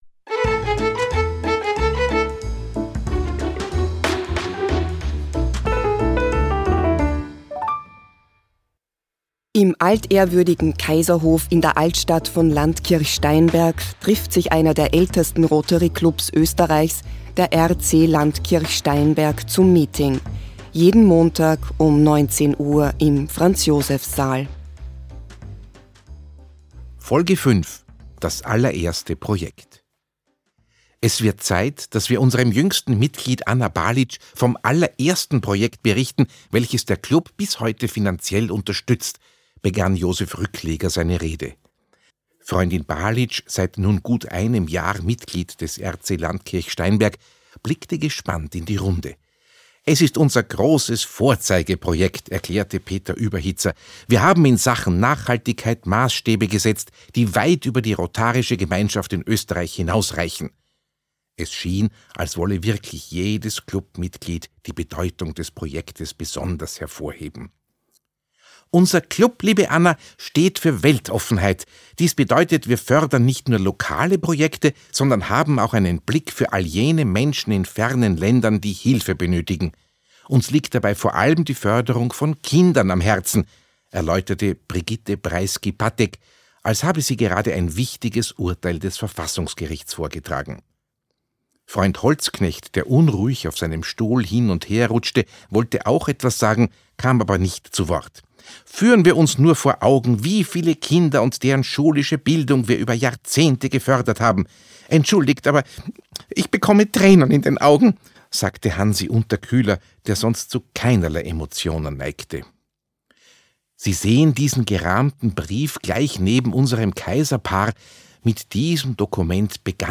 Audio-Comedy